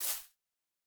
Minecraft Version Minecraft Version 25w18a Latest Release | Latest Snapshot 25w18a / assets / minecraft / sounds / block / azalea / step3.ogg Compare With Compare With Latest Release | Latest Snapshot
step3.ogg